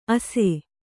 ♪ ase